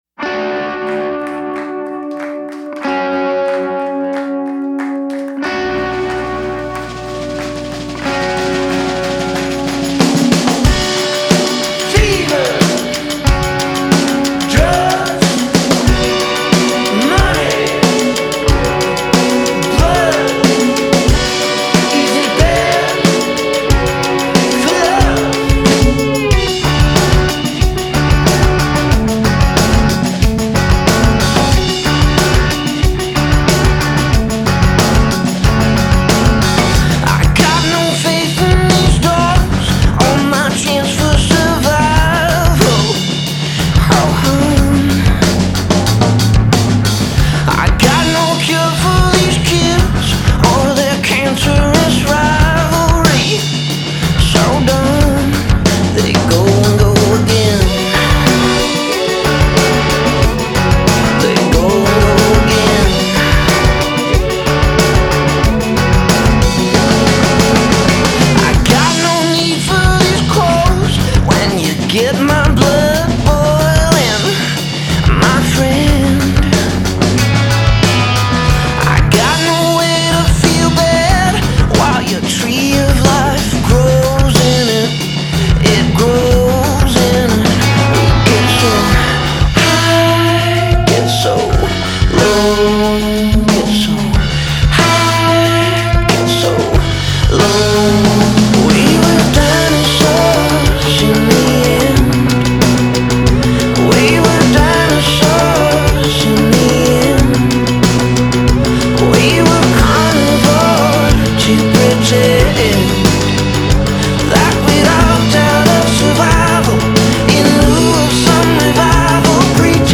alternative band